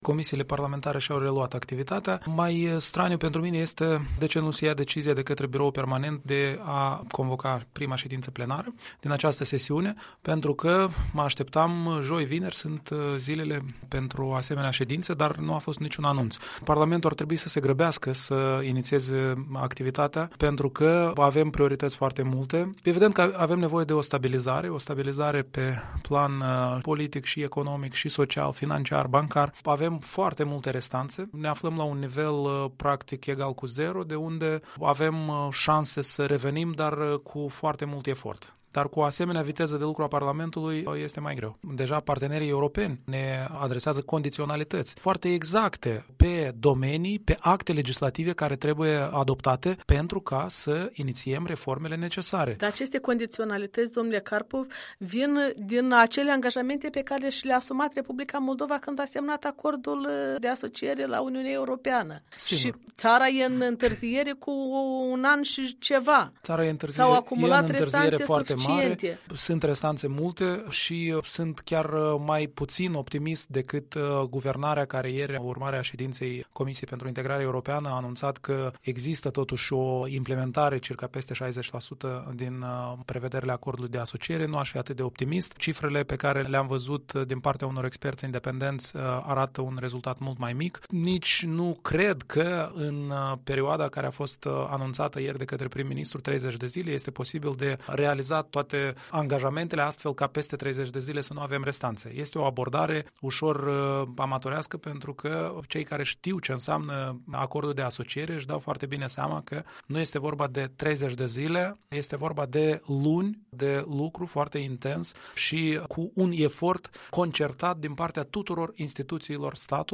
Un interviu cu deputatul independent, afiliat la Partidul Popular European.
Deputatul Eugen Carpov răspunde întrebărilor Europei Libere